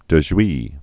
(də zhwē)